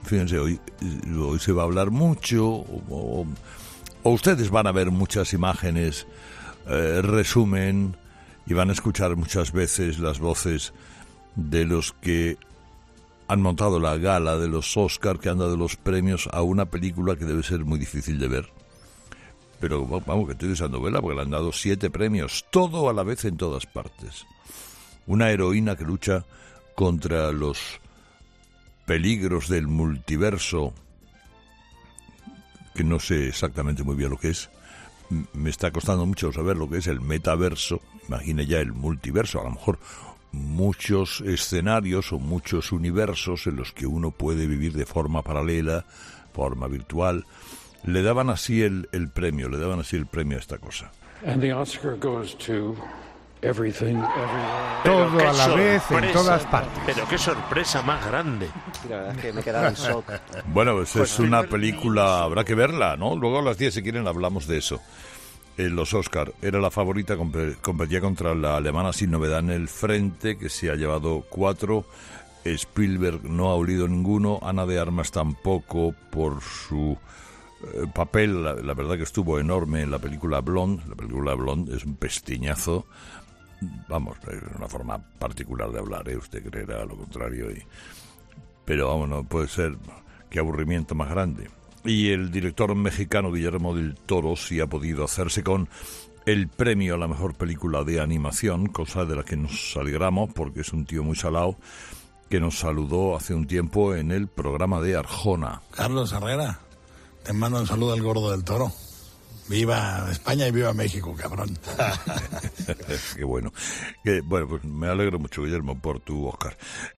"Carlos Herrera, te manda un saludo el gordo Del Toro, viva España y viva México", decía el cineasta, provocando las risas de Carlos Herrera, que le mandaba una felicitación antes de pasar al siguiente asunto del día.